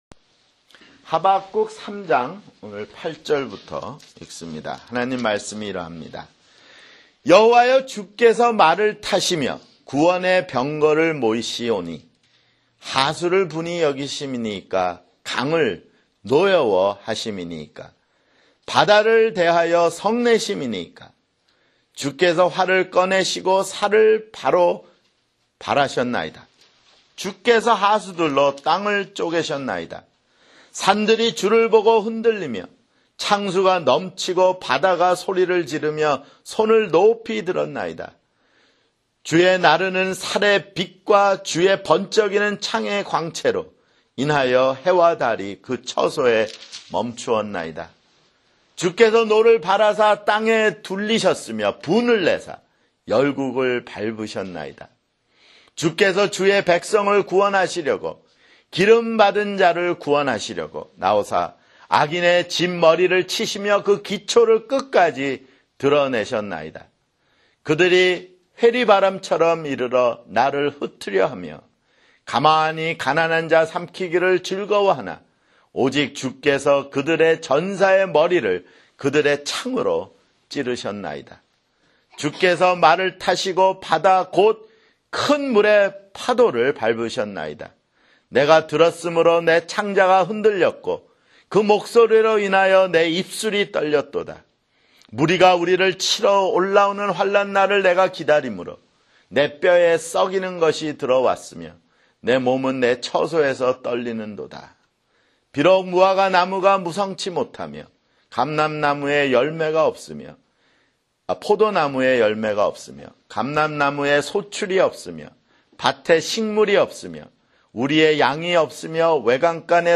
[주일설교] 하박국 (13)